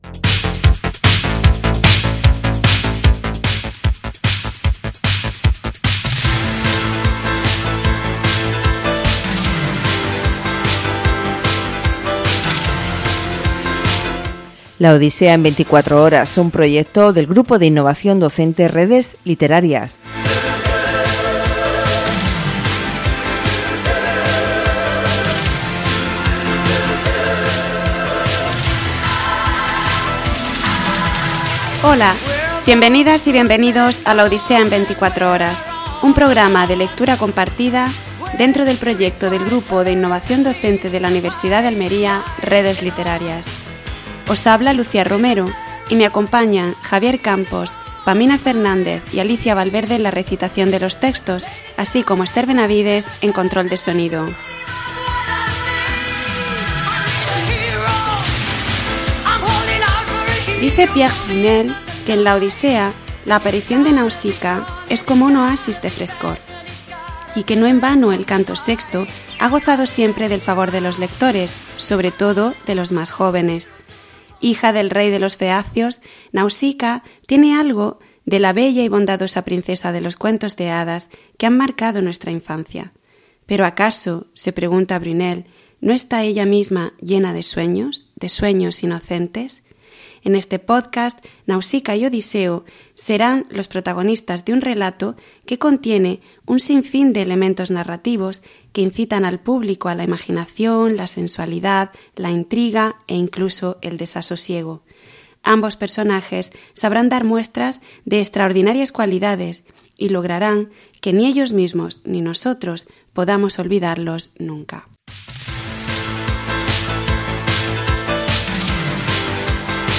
A través de una serie de emisiones en formato podcast, se leerán extractos de la Odisea y se comentarán conforme a puntos de vista que puedan suscitar interés en el público oyente